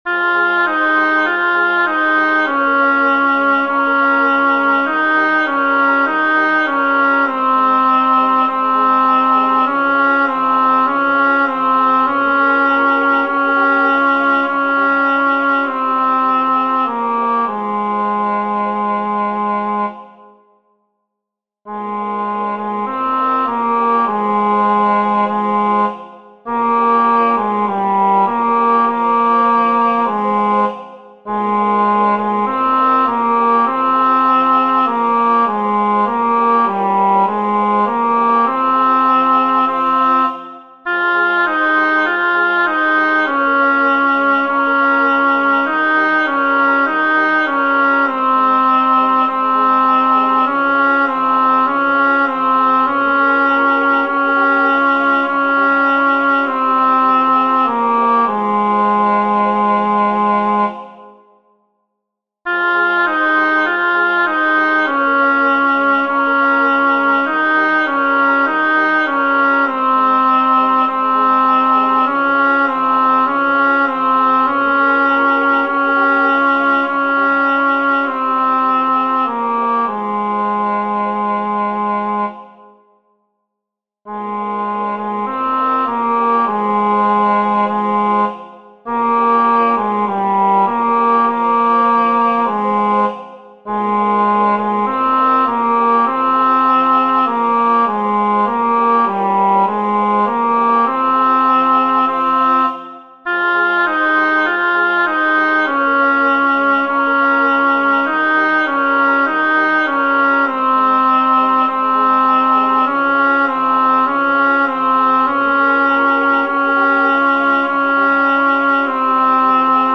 • El tempo indicado es lento.
Aquí os dejo las versiones MIDI de las cuatro voces para su estudio:
veante-mis-ojos-tenor.mp3